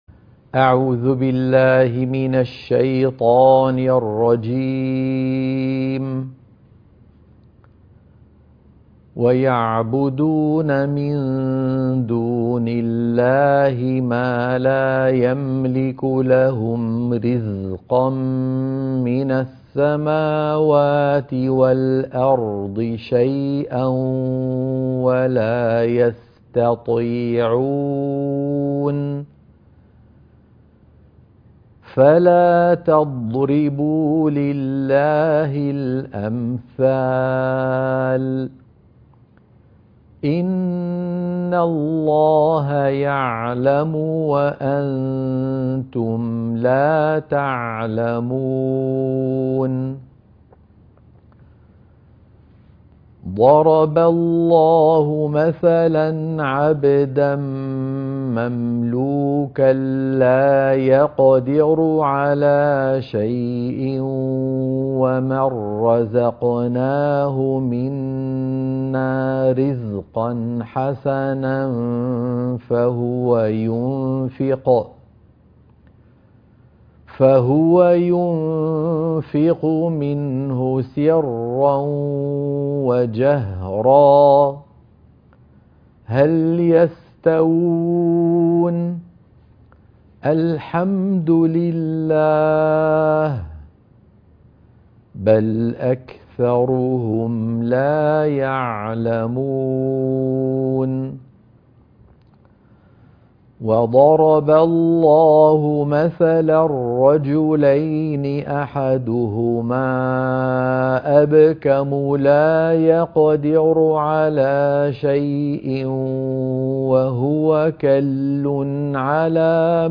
عنوان المادة تلاوة نموذجية لآيات سورة النحل من 73 ل 79